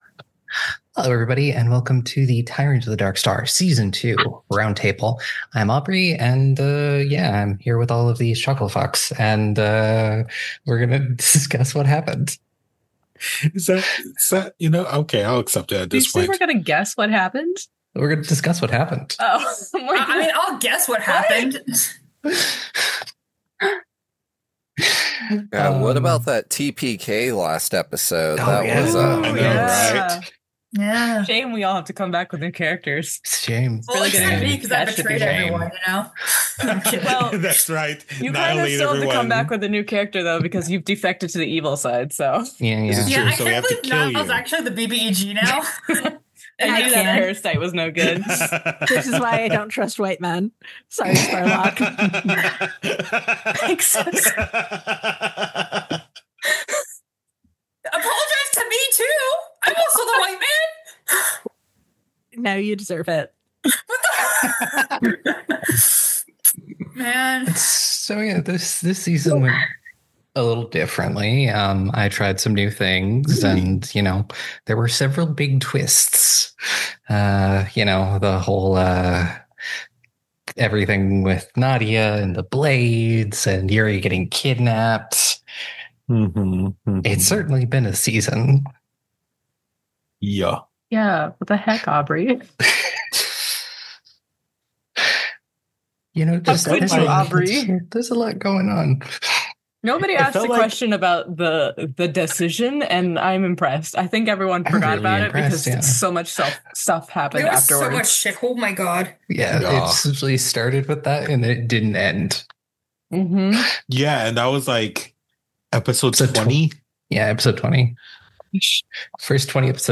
Once again its that time, time to sit down and discuss the season. A lot happened in Season 2 and there is a lot to go over.